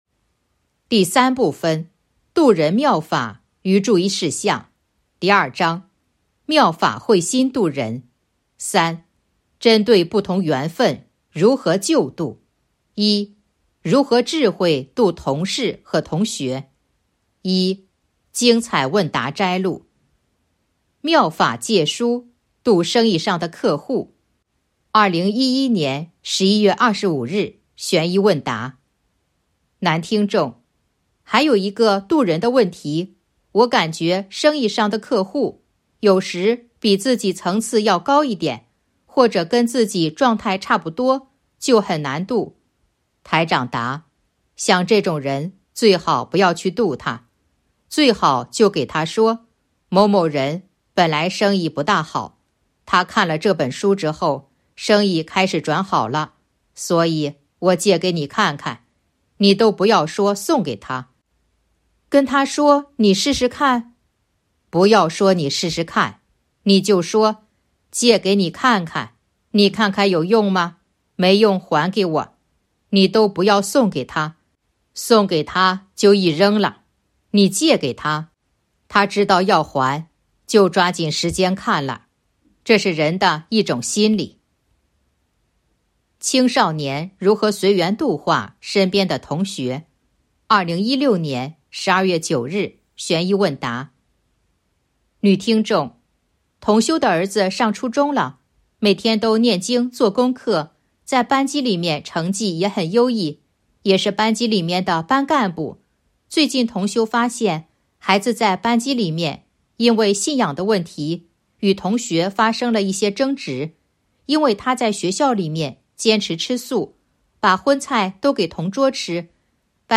精彩问答摘录《弘法度人手册》【有声书】